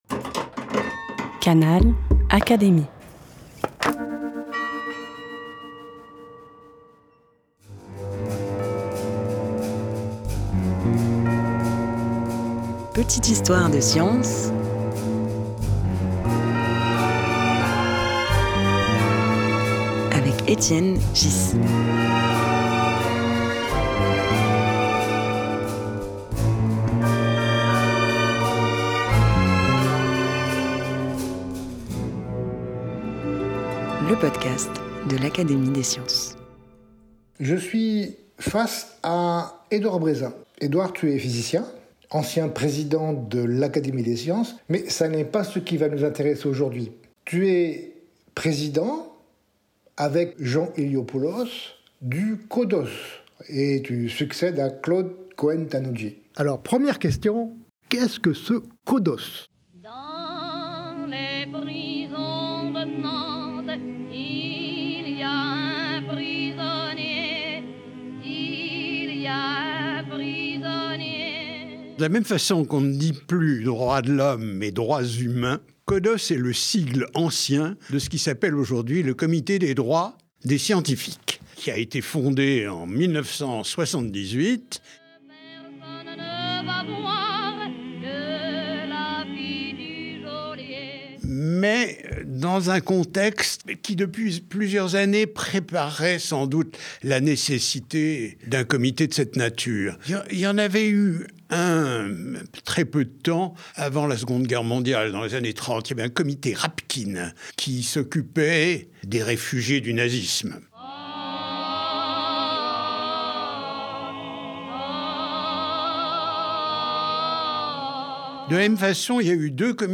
Au micro d’Etienne Ghys, Edouard Brézin, co-président de ce comité, décrit la diversité des situations auxquelles ils font face.
Un podcast animé par Étienne Ghys, proposé par l'Académie des sciences.